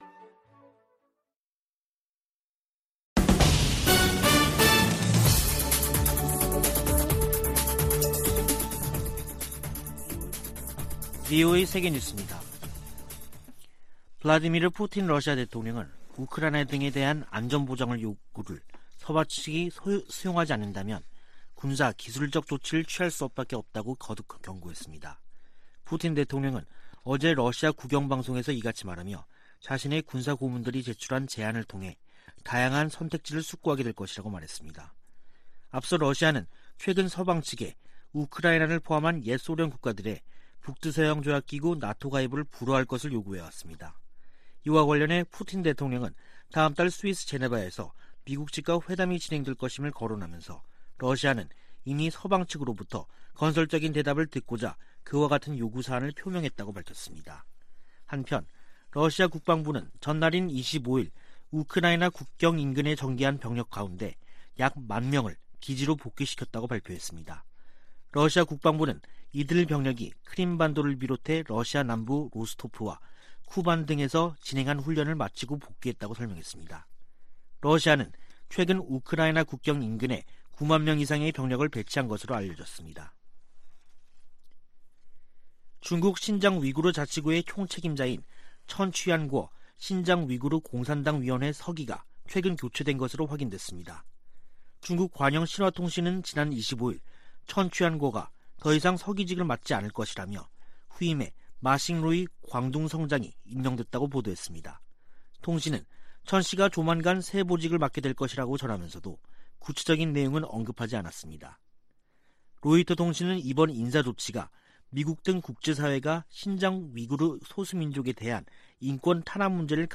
VOA 한국어 간판 뉴스 프로그램 '뉴스 투데이', 2021년 12월 27일 2부 방송입니다. 2022년 새해를 앞두고 조 바이든 미국 행정부의 대북 전략에 대한 전문가들의 전망과 제언이 이어지고 있습니다. 로버트 에이브럼스 전 주한 미군사령관은 미국과 한국이 연합훈련 일부 재개를 진지하게 논의할 시점이라고 말했습니다. 북한이 일부 경제 부문의 성과를 부각하며 올 한 해를 승리의 해라고 연일 선전하고 있습니다.